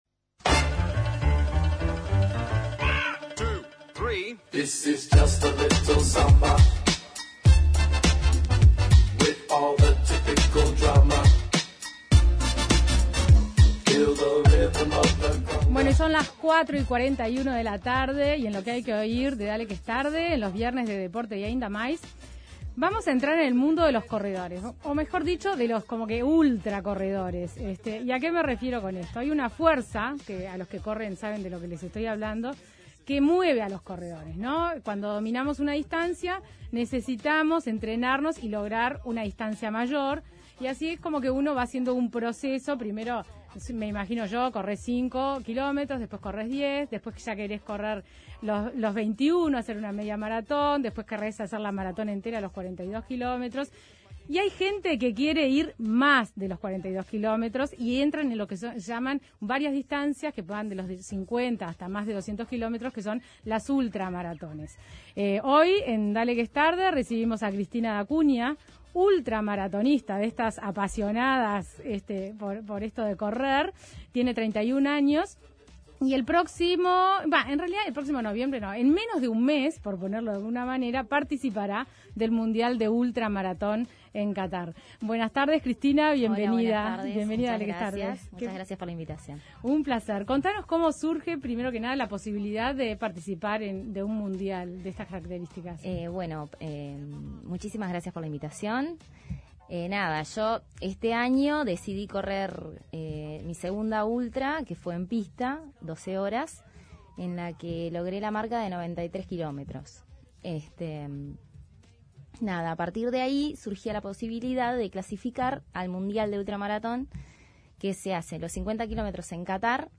podés escuchar la entrevista completa en Dale Que Es Tarde.